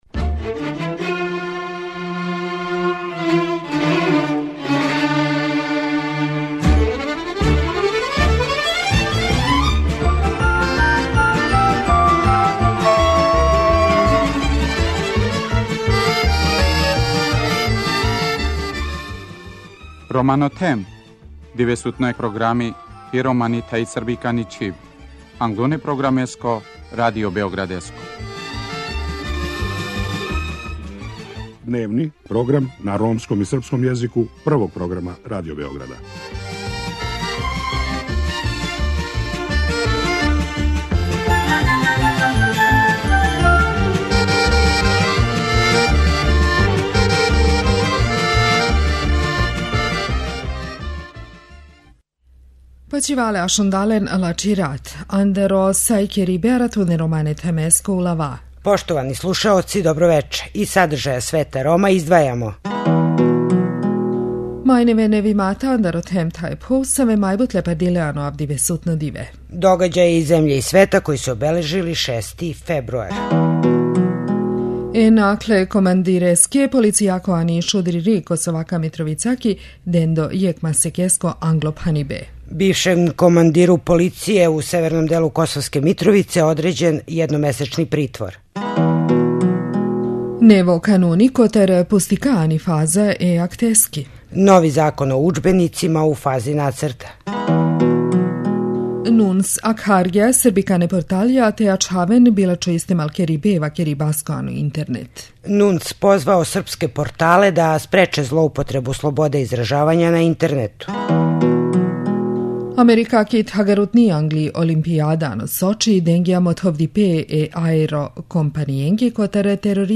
Сузана Пауновић, директорка Канцеларије за људска и мањинска права говори о резултатима спровођења Стратегије за унапређење положаја Рома. Од 2009. године у матичне књиге рођених уписано је 20.679 Рома, а известан помак је остварен и у области образовања и здравства.
преузми : 19.10 MB Romano Them Autor: Ромска редакција Емисија свакодневно доноси најважније вести из земље и света на ромском и српском језику.